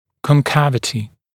[kɔn’kævətɪ][кон’кэвэти]вогнутость